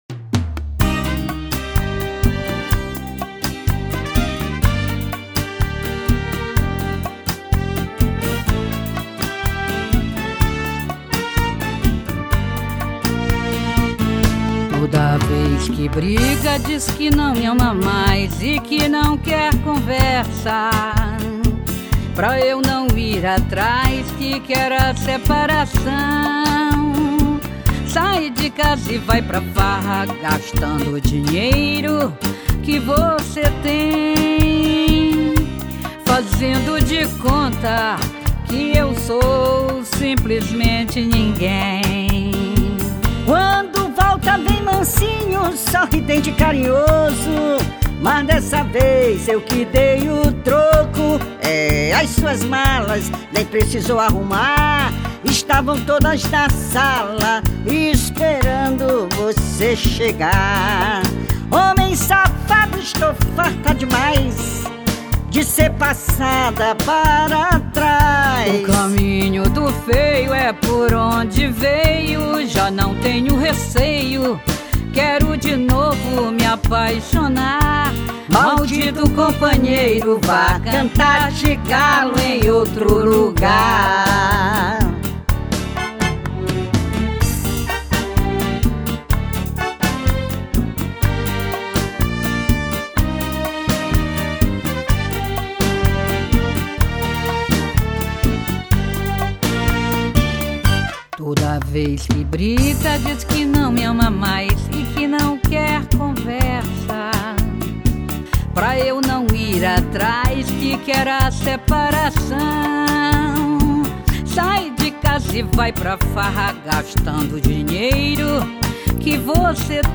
EstiloMPB
Cidade/EstadoSão Luís / MA